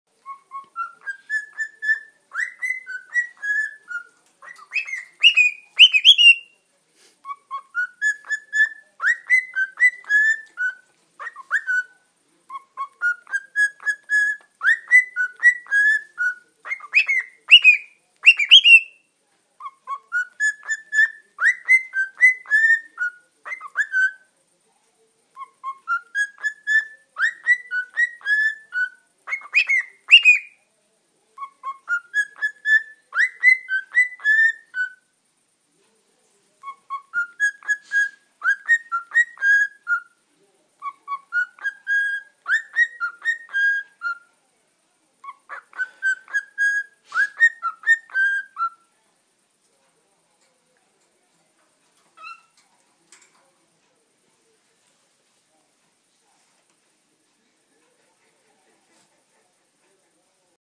دانلود آهنگ عروس هلندی 1 از افکت صوتی انسان و موجودات زنده
جلوه های صوتی